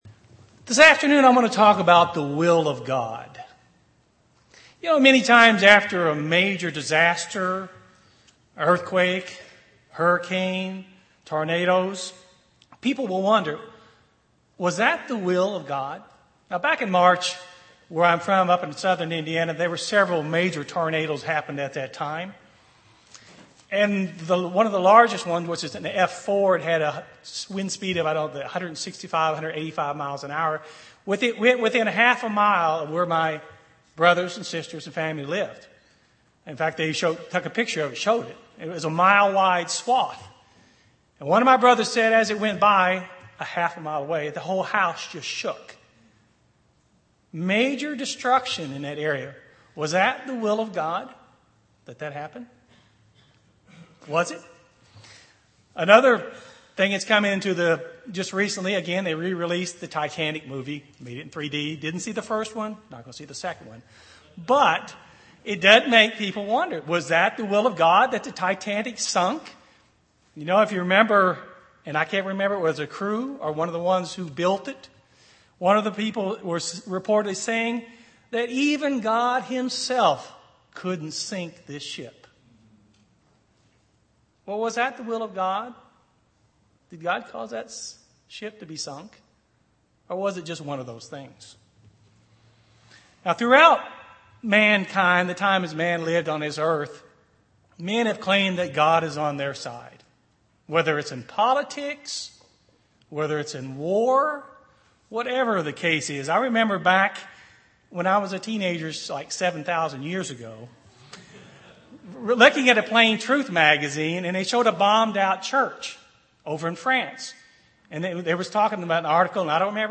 Given in East Texas
UCG Sermon Studying the bible?